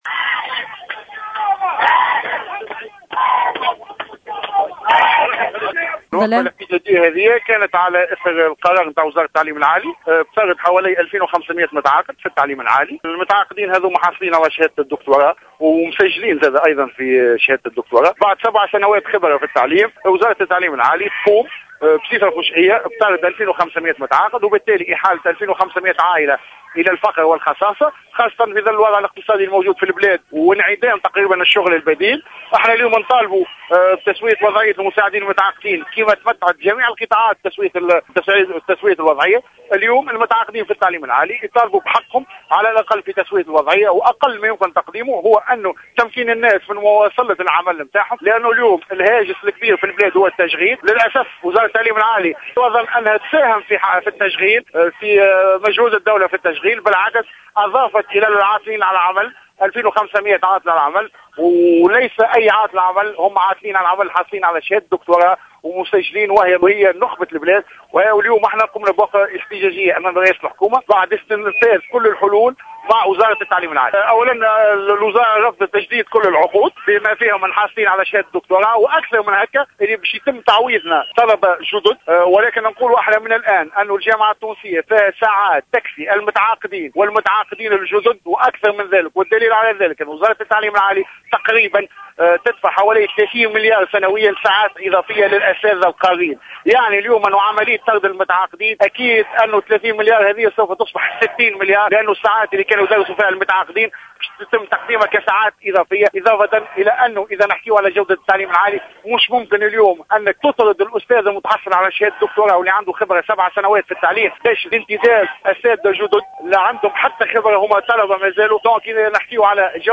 أحد المحتجين